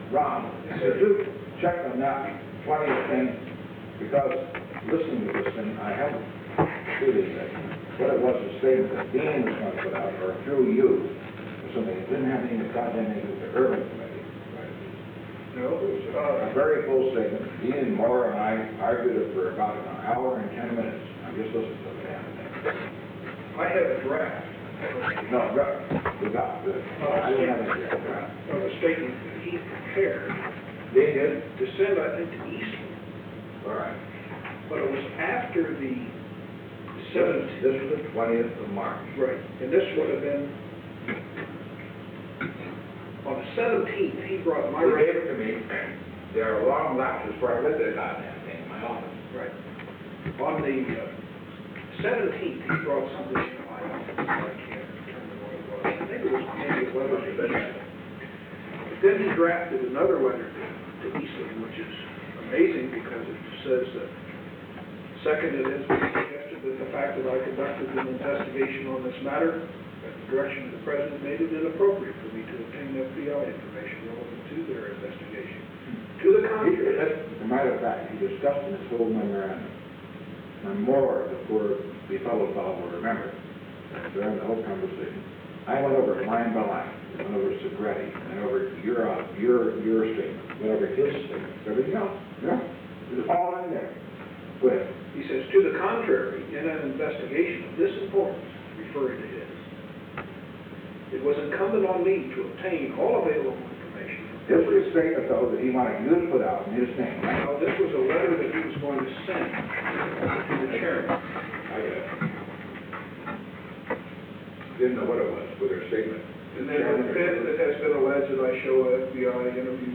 Conversation No. 442-61 Date: June 4, 1973 Time: 8:40 pm - 8:55 pm Location: Executive Office Building The President met with Ronald L. Ziegler.
Secret White House Tapes